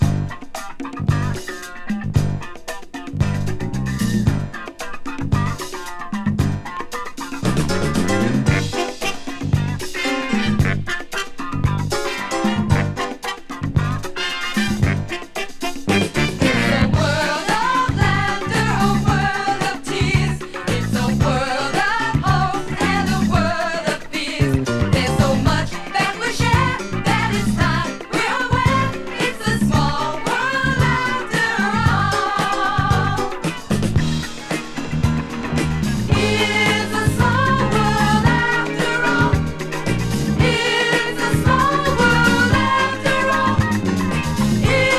Pop, Disco, Novelty　USA　12inchレコード　33rpm　Stereo